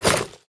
addininventory.wav